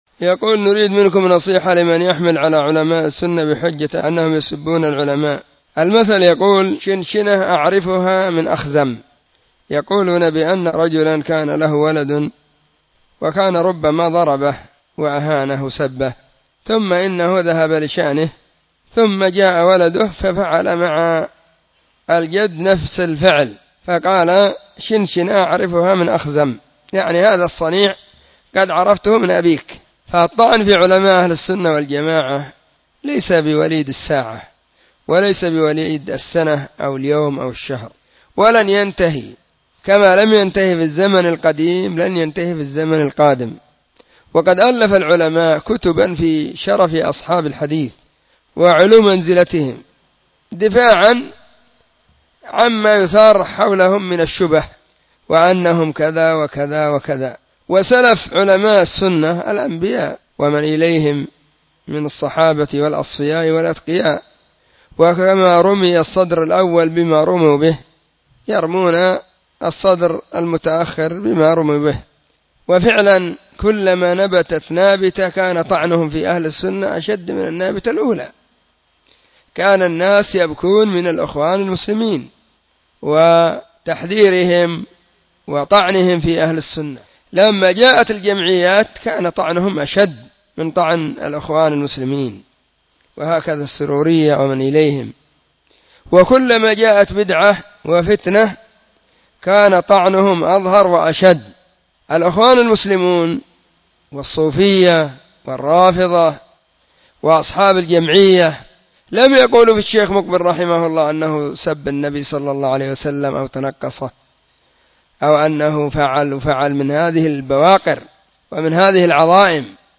🔸🔹 سلسلة الفتاوى الصوتية المفردة 🔸🔹
📢 مسجد الصحابة – بالغيضة – المهرة، اليمن حرسها الله.